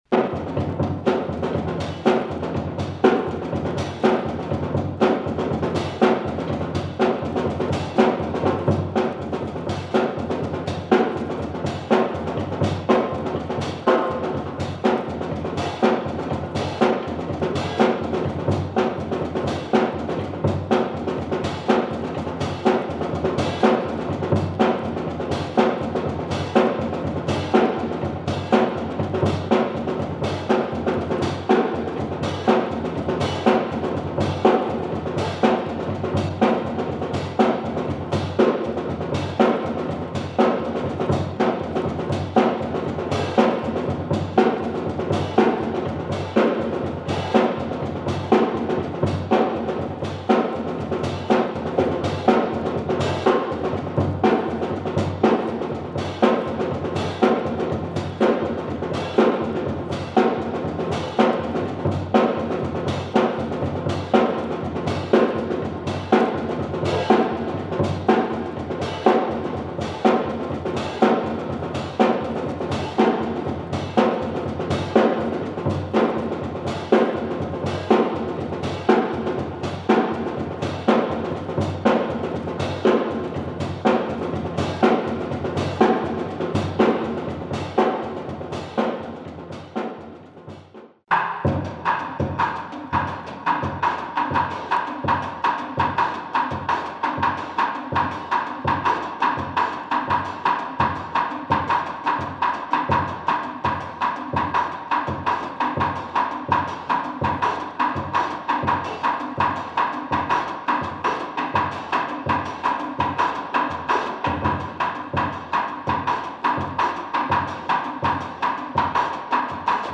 Killer jazz training album from drummer
Including jazz groove, dope breakbeats